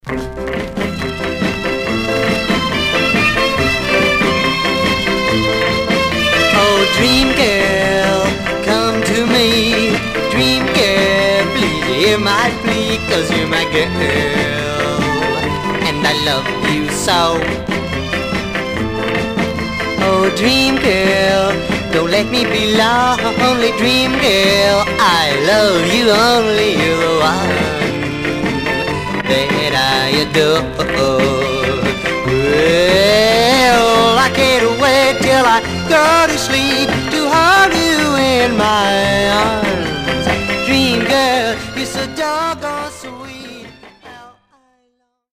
Some surface noise/wear Stereo/mono Mono
Teen